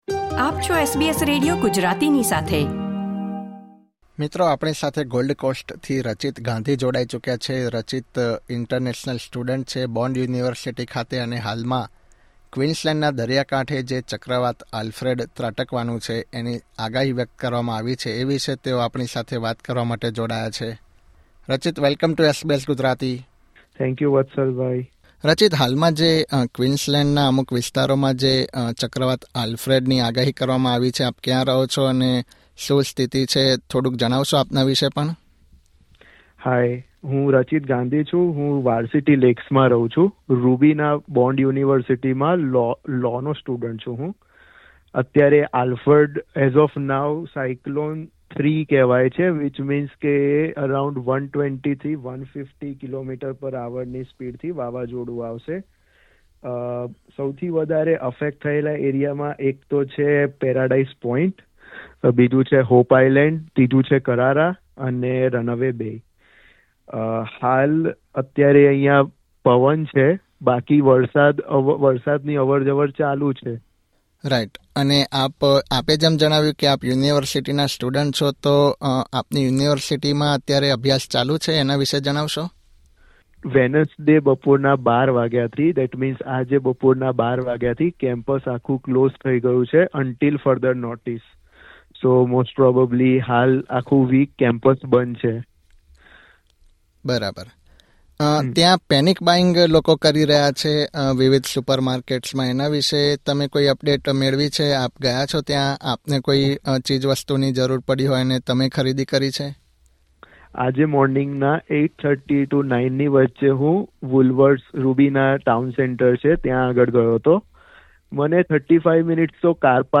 International student describes situation in Gold Coast as Tropical Cyclone Alfred's crossing labelled 'very rare and hazardous event'